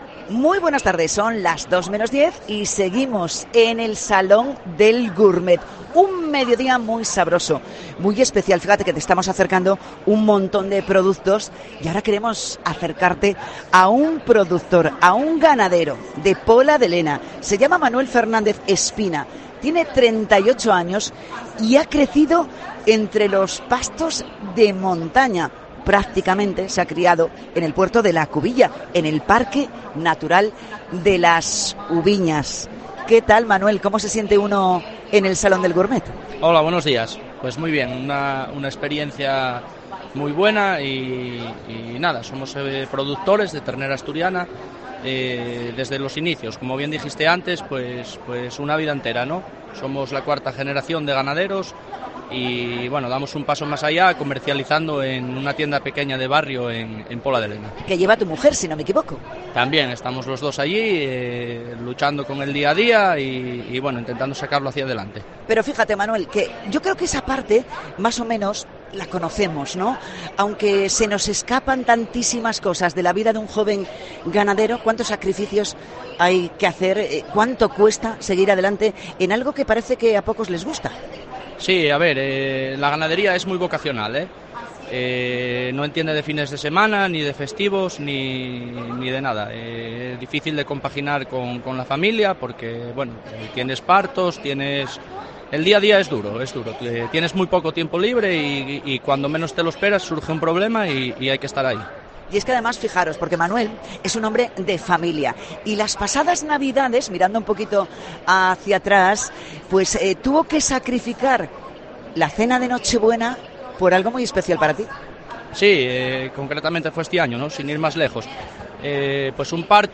Entrevista
en el Salón Gourmets